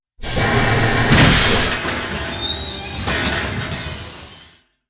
Puerta de una nave espacial: chirrido
puerta
chirrido
Sonidos: Especiales
Sonidos: Fx web